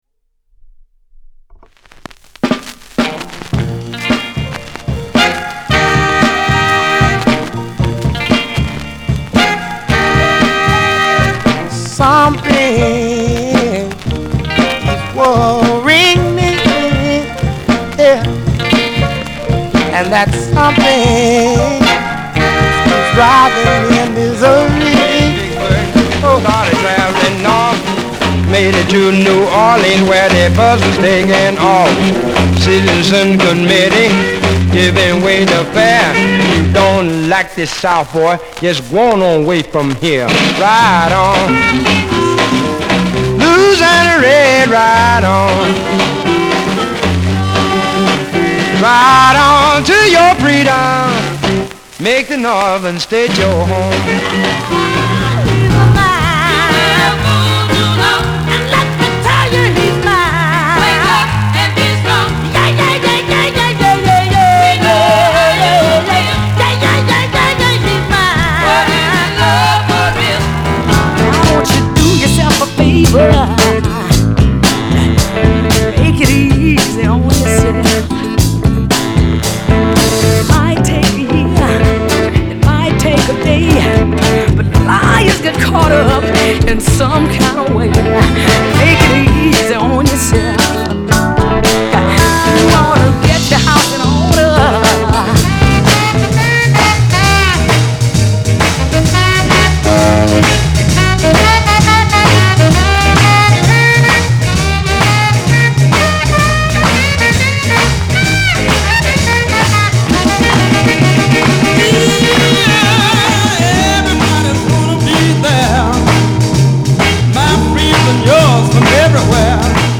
ブルース